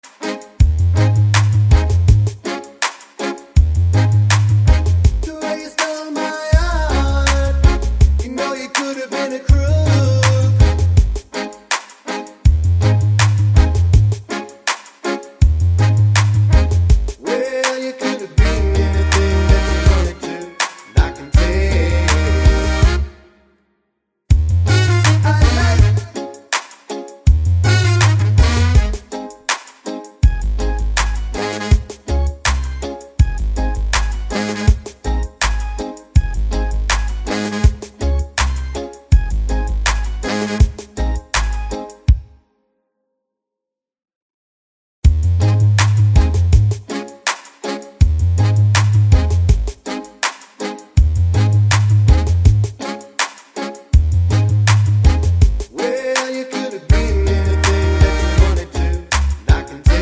no Backing Vocals Reggae 3:05 Buy £1.50